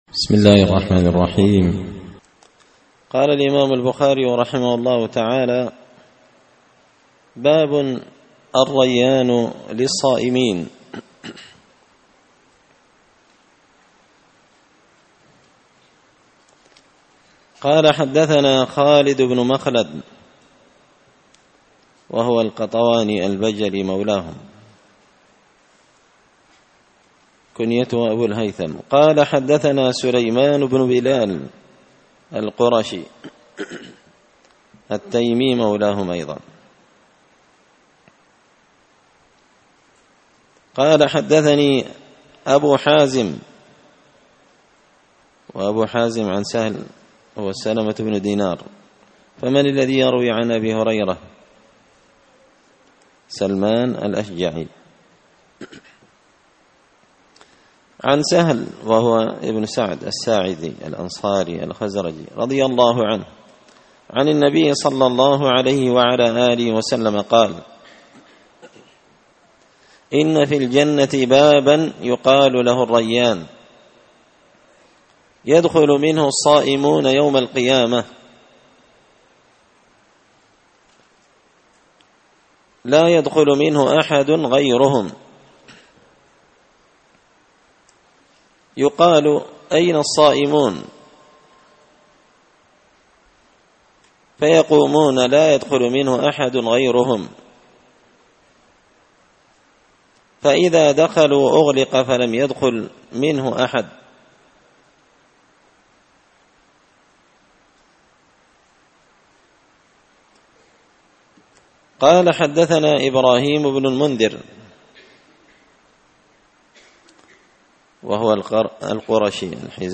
كتاب الصيام من صحيح البخاري الدرس الرابع (4) باب الريان للصائمين
مسجد الفرقان قشن_المهرة_اليمن